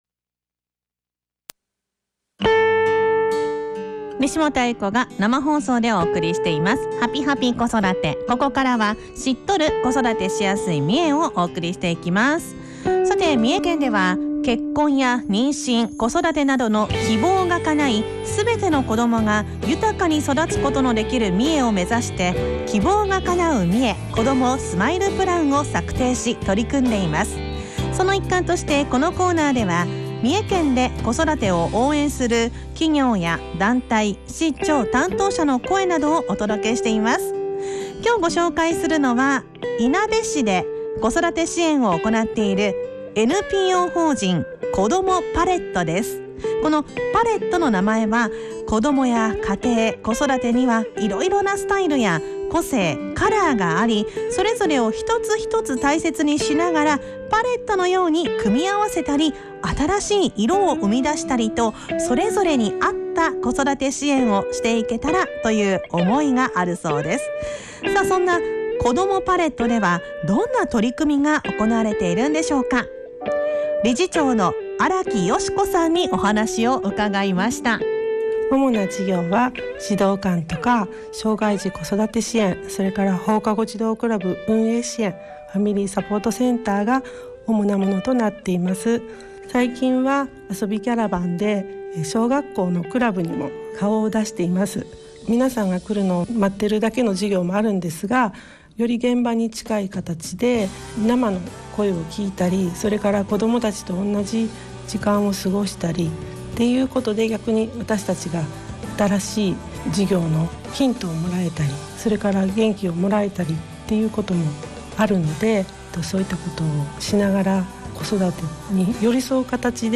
インタビュー
県内各市町の子育てを応援している団体などを取材し、地域で活躍されている方の生の声をFM三重「はぴぱぴ子育て」「EVENING COASTER」内で放送しました。